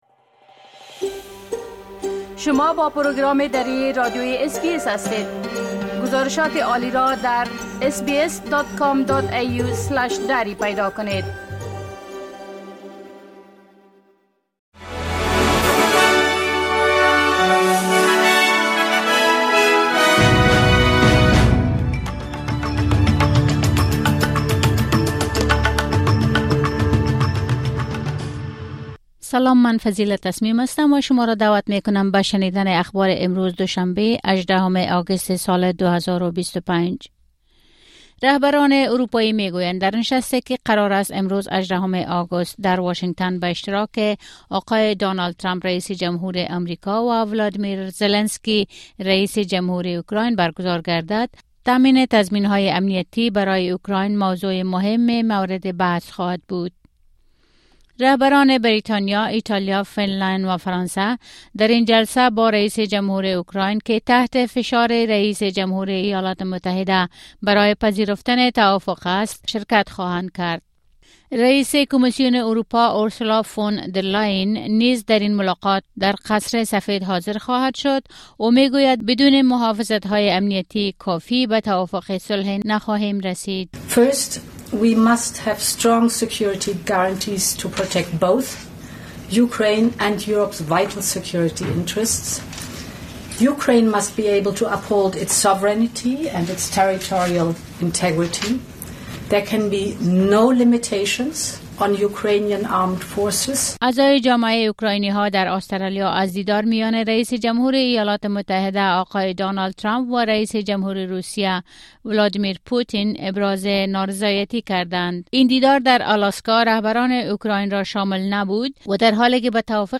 خلاصۀ مهمترين خبرهای روز از بخش درى راديوى اس‌بى‌اس